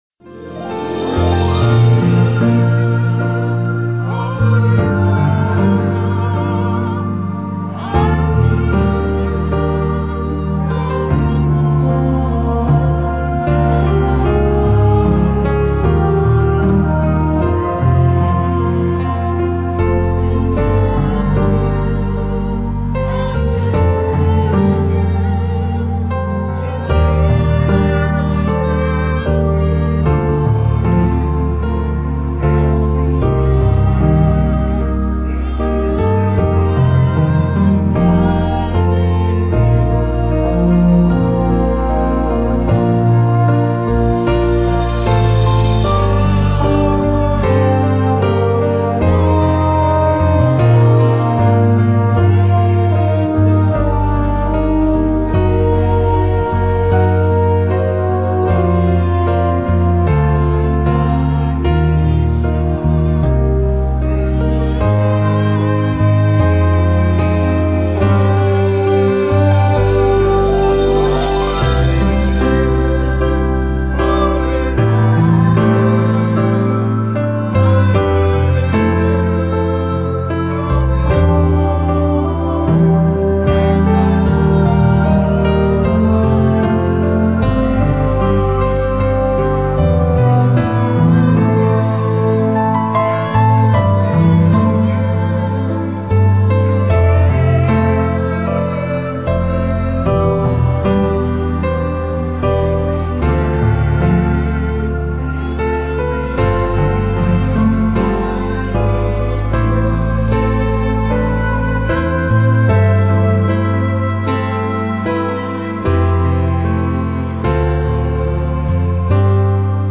Sung by ABC Choir.